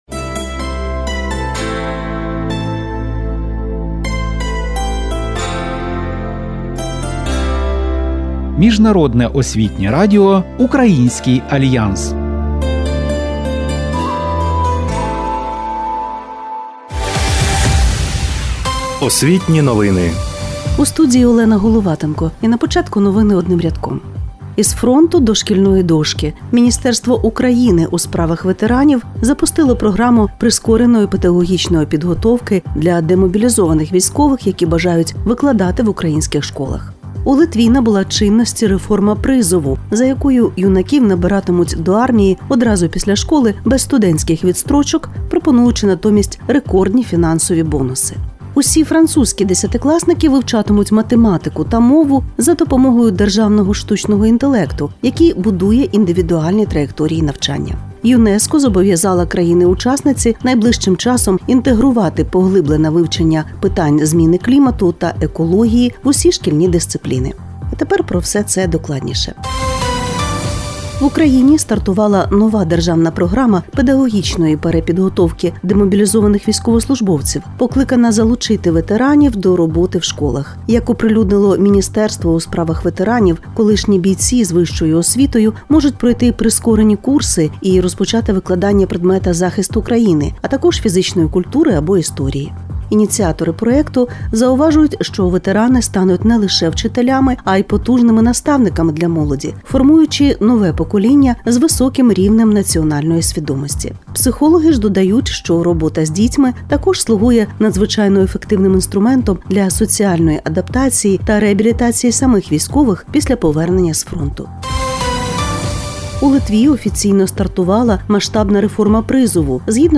Цей випуск об’єднує важливі новини про світові освітні інновації, екскурс у драматичні історичні події України та Литви, дієві поради щодо вивчення французької мови, занурення в українську альтернативну музику, практичний урок литовської та щемливу поезію Павла Тичини у сучасному звучанні.